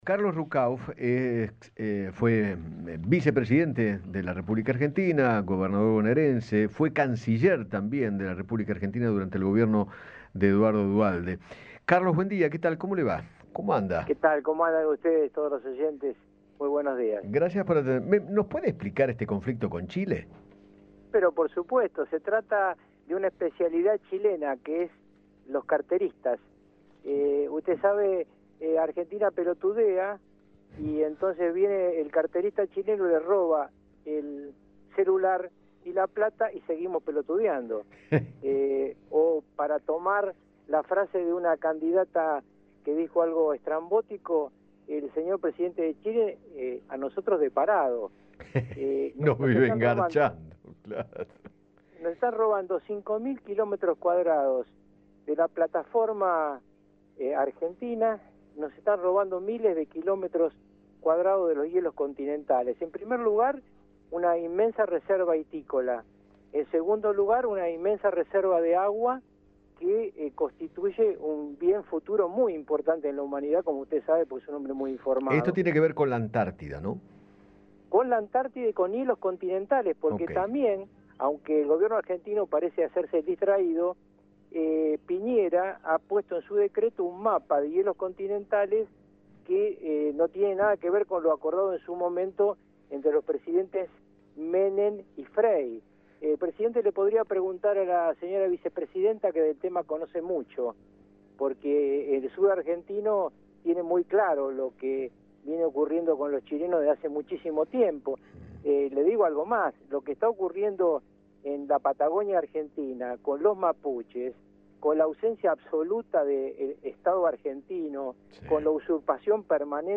Carlos Ruckauf, ex vicepresidente de la Nación, conversó con Eduardo Feinmann sobre el conflicto con el país vecino por la actualización de los límites marítimos, agregando 200 millas que le pertenecen a Argentina sin respetar el Acuerdo de Paz y Amistad firmado en 1984.